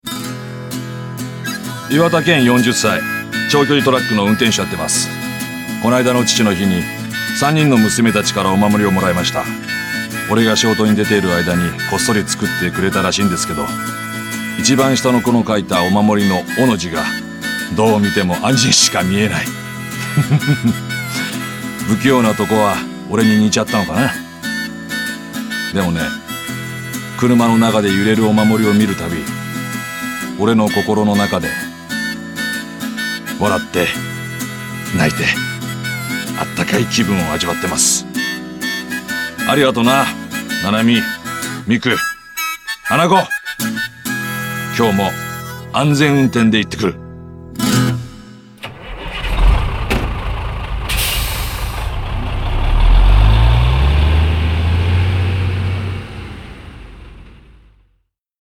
低音を活かしナレーションでも活躍中。